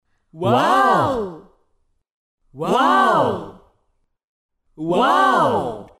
众人惊叹哇哦音效免费音频素材下载